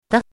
怎么读
de
de5.mp3